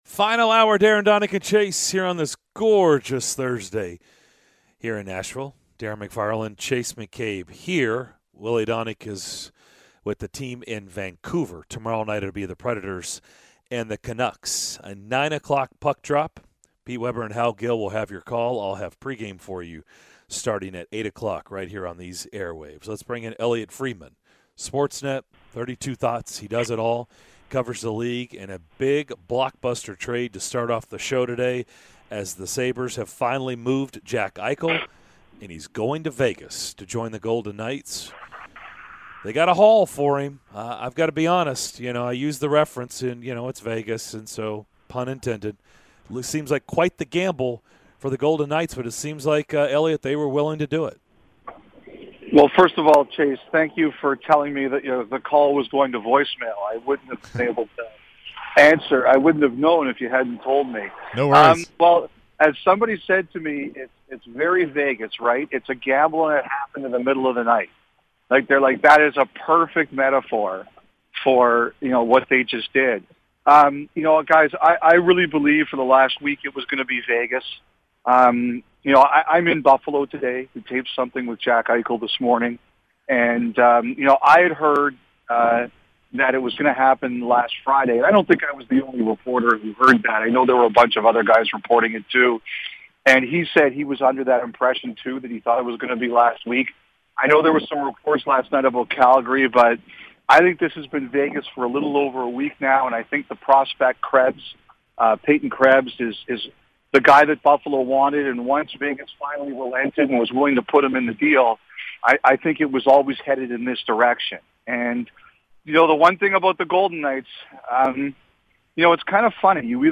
Sportsnet's Elliotte Friedman joined the DDC to give his thoughts on the Jack Eichel trade and the latest from around the NHL!